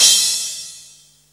43_08_cymbal.wav